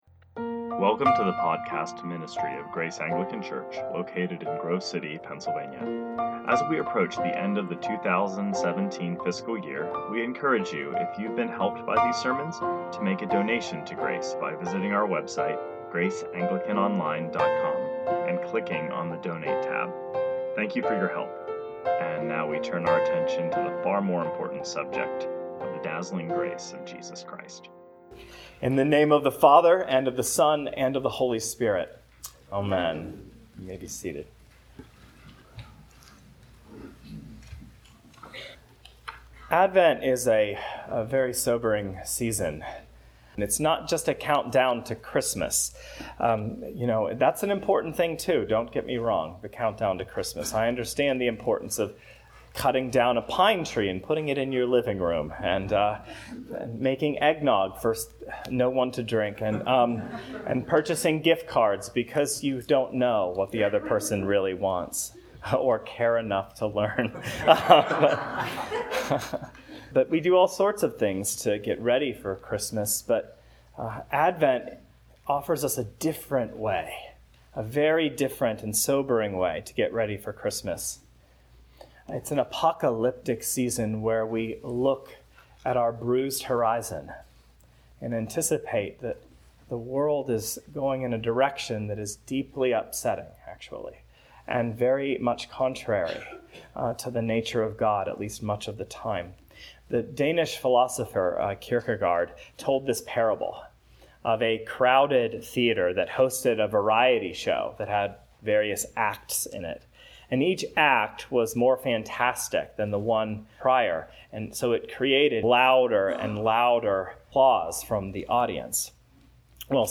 2019 Sermons